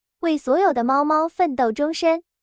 Convert Text to Speech with Llasa
The output will be a URI link to the generated speech audio file, allowing you to easily access and play the audio.